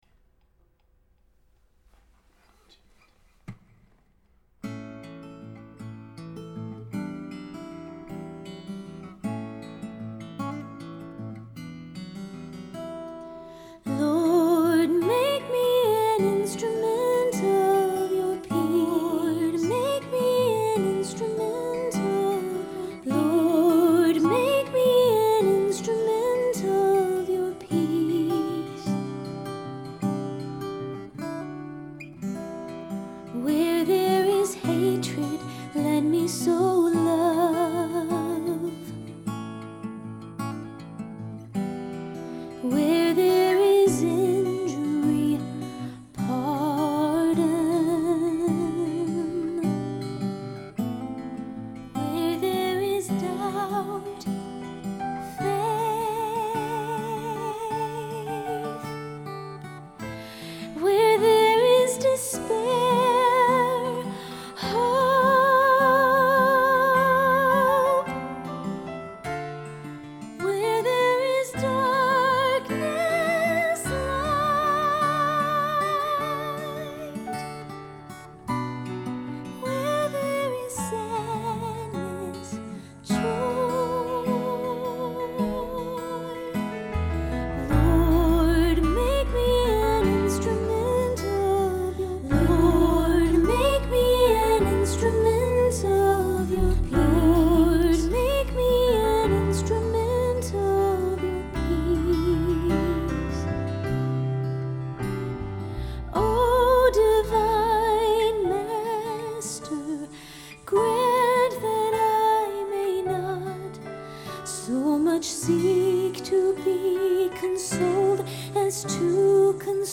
Unison with optional SSA